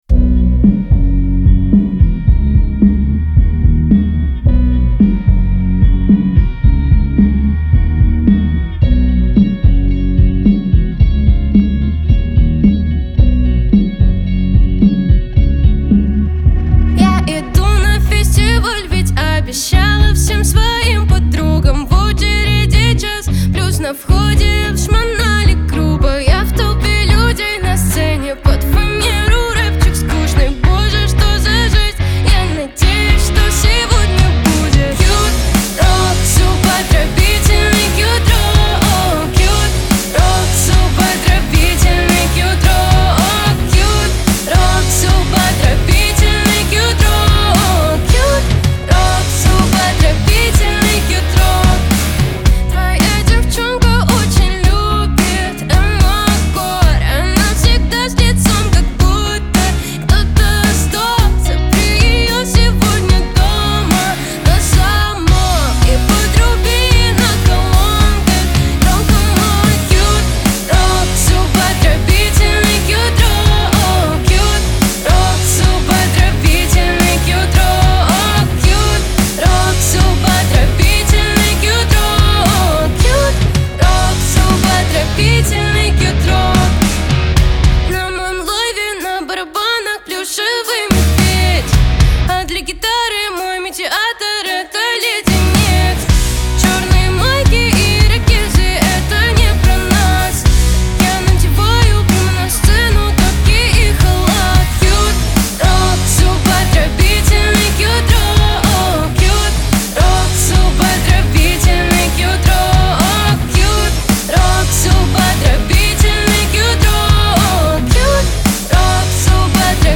отличается гармоничными гитарными рифами и задорными ритмами